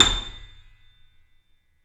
Index of /90_sSampleCDs/Roland - Rhythm Section/KEY_YC7 Piano mf/KEY_mf YC7 Mono
KEY A 6 F 0P.wav